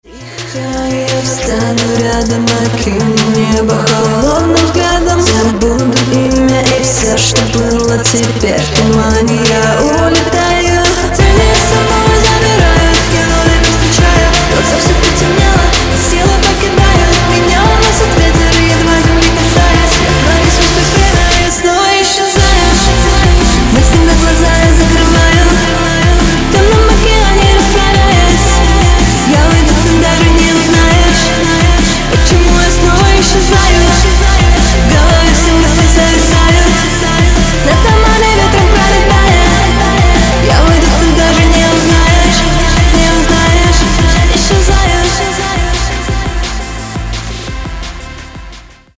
• Качество: 320, Stereo
женский вокал
Pop Rock
поп-рок